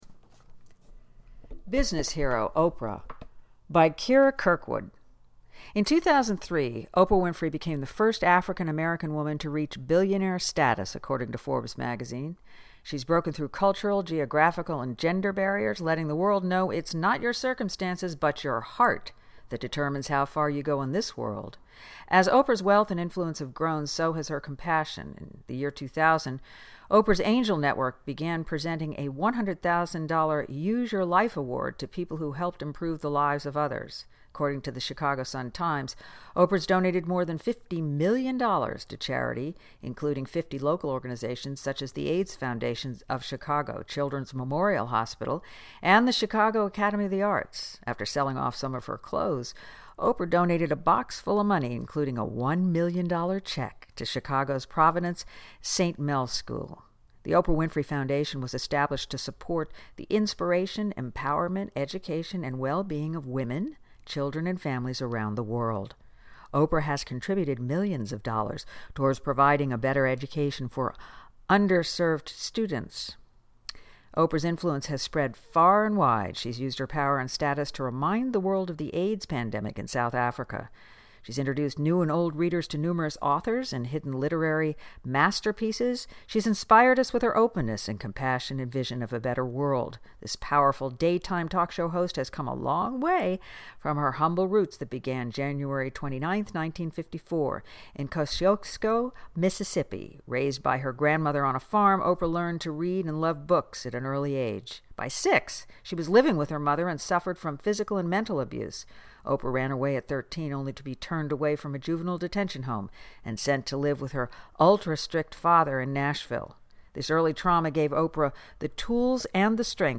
Please enjoy this reading of our MY HERO story